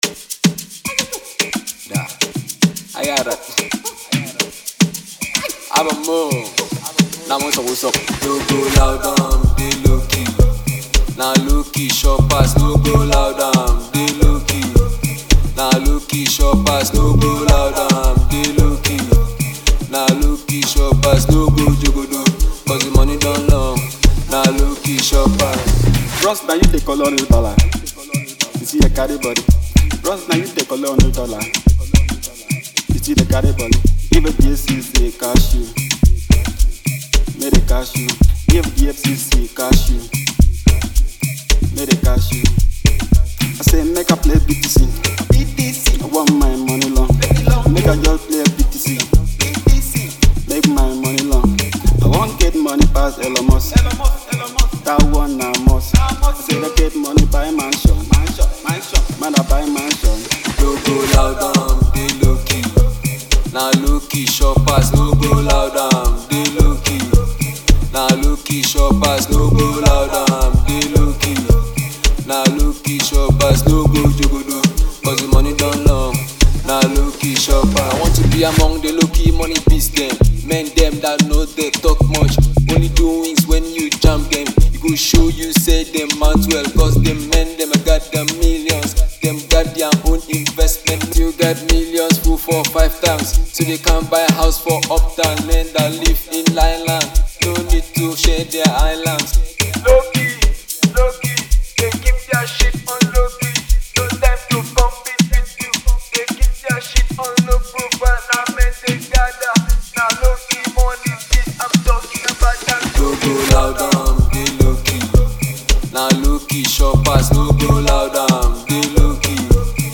Afrobeats
With a laid-back yet infectious beat